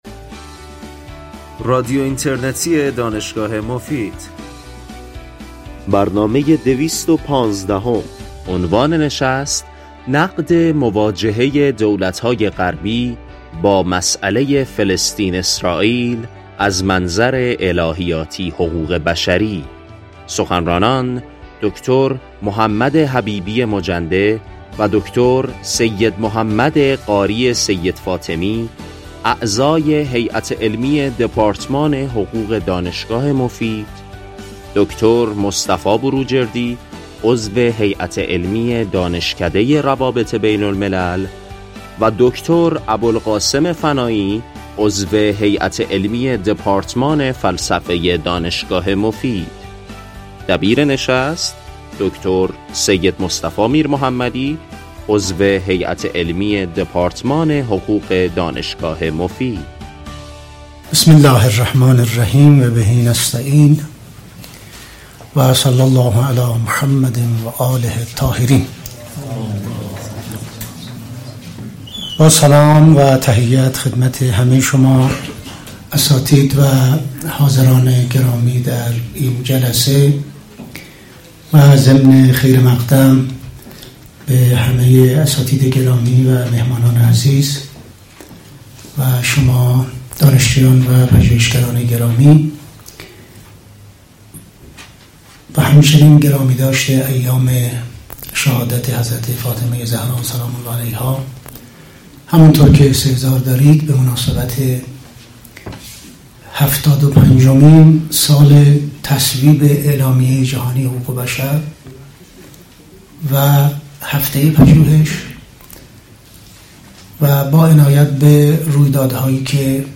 سخنرانان در این نشست علمی، مهمترین علل تاریخی، فلسفی، سیاسی، عقیدتی و… حمایت کشورهای غربی (عمدتاً کشورهای اروپای سفید و امریکا) از اسراییل را به بحث می‌گذارند و با بهره گیری از ظرفیت‌های نظام بین المللی حقوق بشر راهکارهایی برای مقابله با جنایات اسراییل در حق مردم مظلوم فلسطین ارائه می‌نمایند.